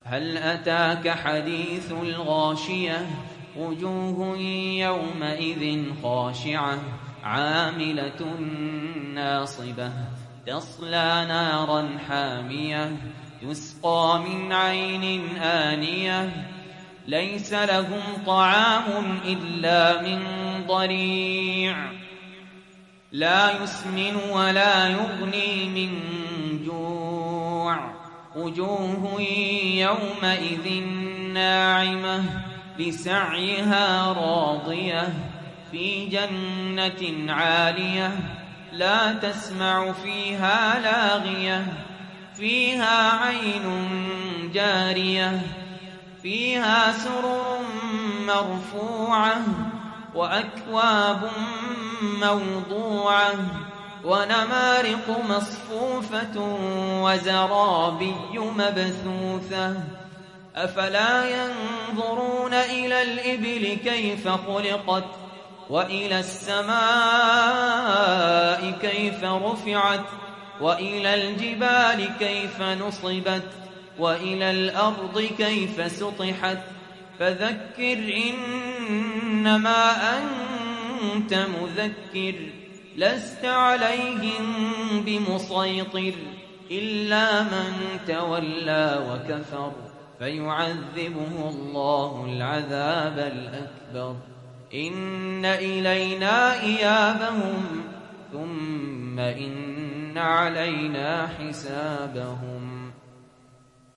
تحميل سورة الغاشية mp3 سهل ياسين (رواية حفص)